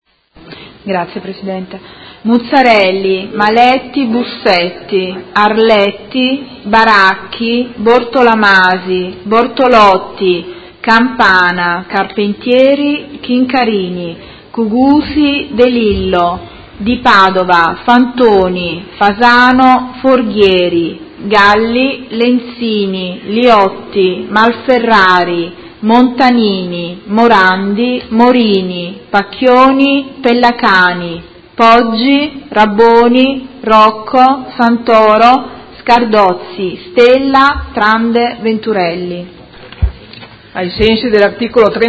Segretaria — Sito Audio Consiglio Comunale
Seduta del 12/04/2018 Appello.